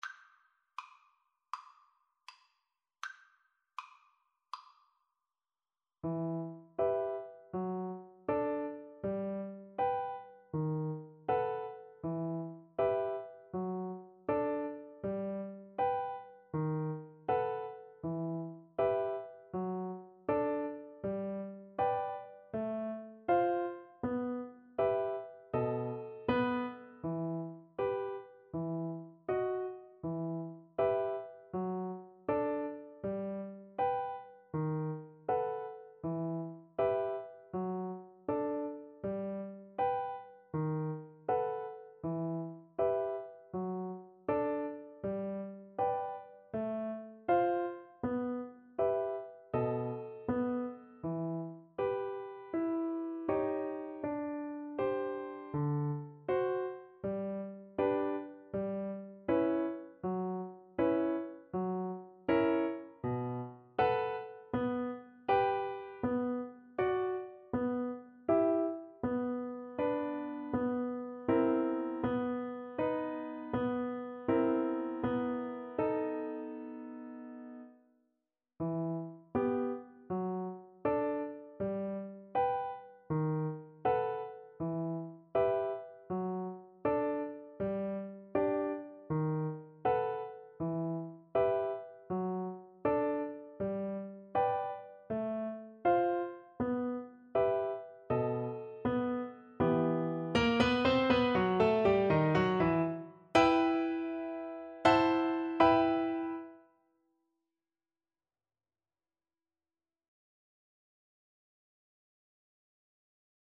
Andante = c.80
Classical (View more Classical Cello Music)